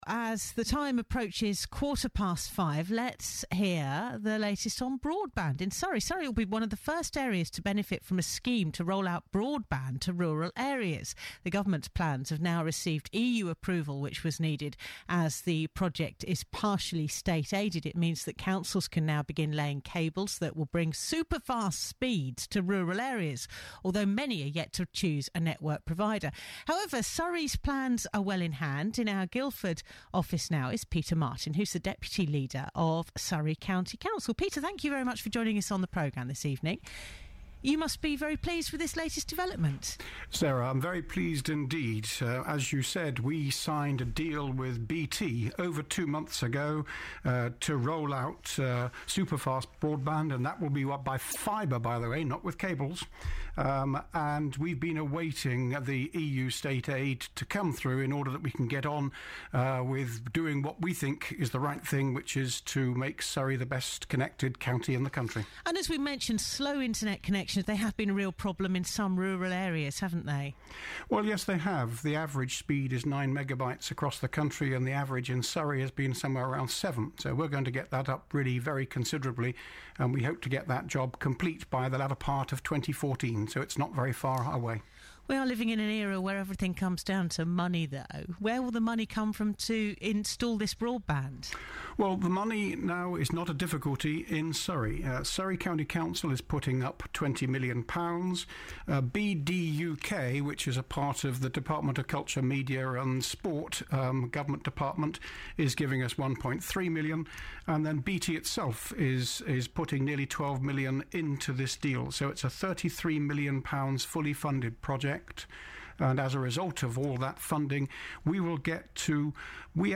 Deputy Leader of Surrey County Council was interviewed about how plans to roll out superfast broadband to virtually every home and business in Surrey can now go ahead, following a green light from the EU.